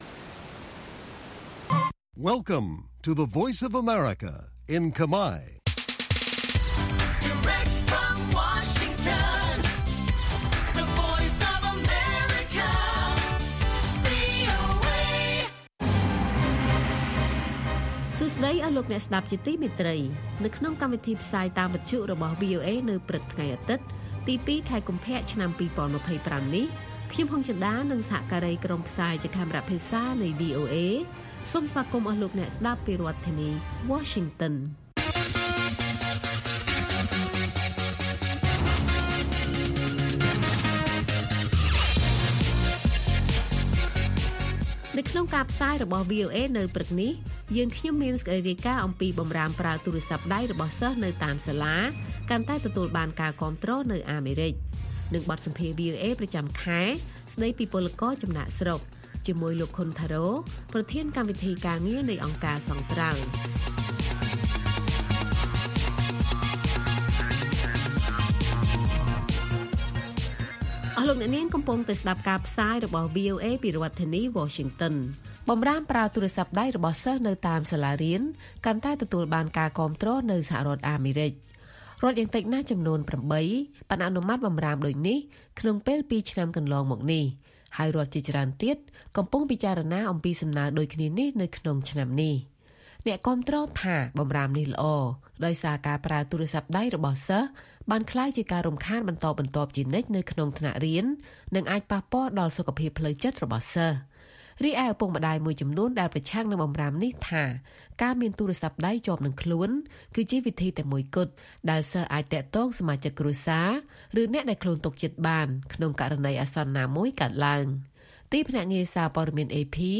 ព័ត៌មាននៅថ្ងៃនេះមានដូចជា បម្រាមប្រើទូរស័ព្ទដៃរបស់សិស្សនៅតាមសាលាកាន់តែទទួលបានការគាំទ្រនៅអាមេរិក។ បទសម្ភាសន៍ VOA ប្រចាំខែស្តីពី «ពលករចំណាកស្រុក»